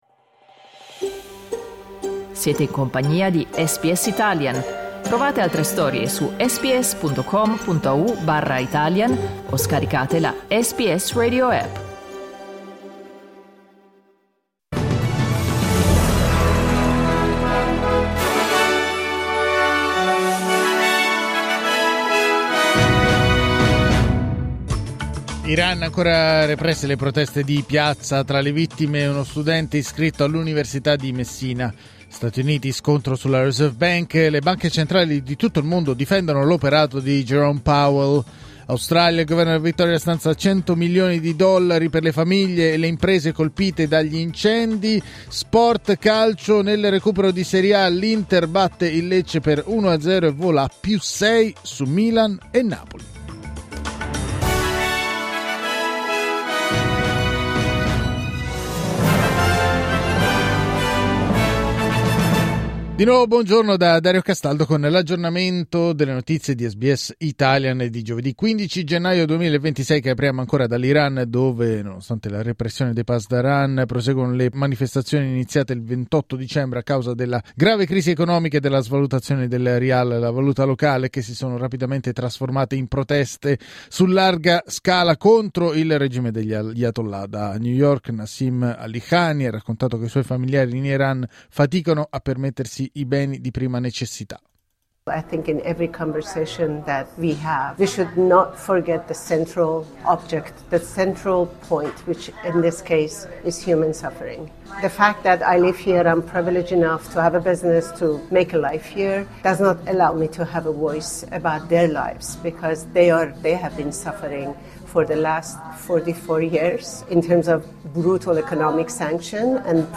News flash giovedì 15 gennaio 2026
L’aggiornamento delle notizie di SBS Italian.